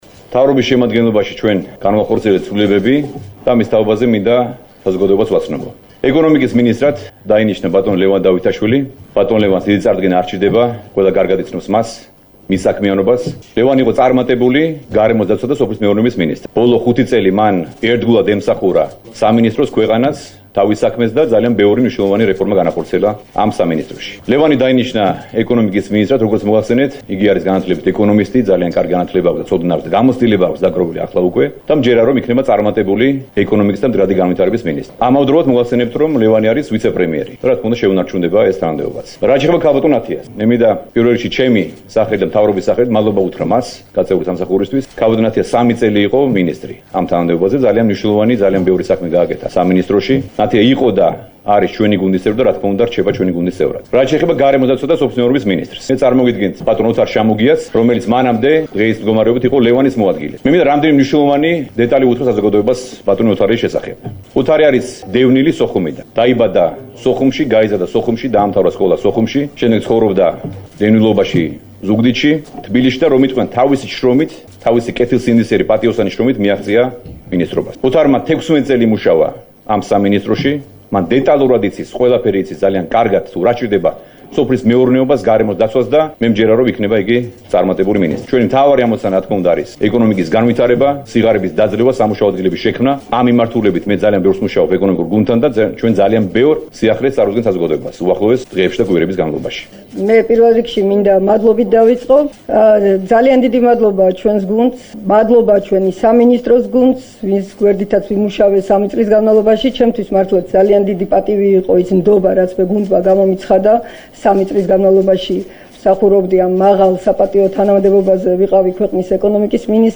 მინისტრთა კაბინეტში ცვლილებების შესახებ, მთავრობის ადმინისტრაციაში გამართულ ბრიფინგზე, თავად პრემიერმა ირაკლი ღარიბაშვილმა ისაუბრა.
ირაკლი ღარიბაშვილის ნათია თურმავას ლევან დავითაშვილის და ოთარ შამუგიას ხმა